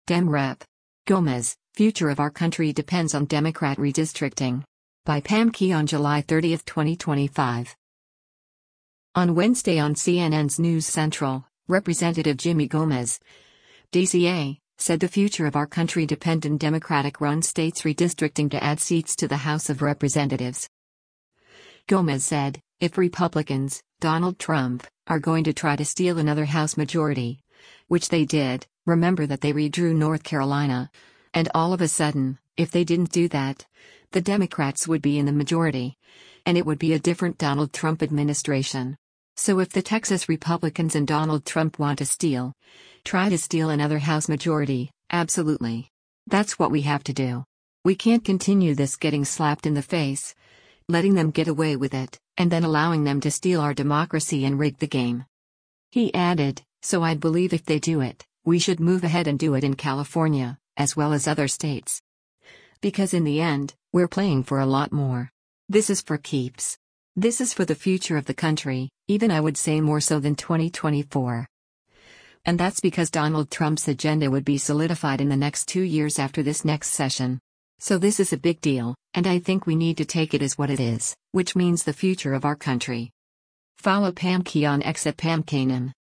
On Wednesday on CNN’s “News Central,” Rep. Jimmy Gomez (D-CA) said “the future of our country” dependedon Democratic-run states redistricting to add seats to the House of Representatives.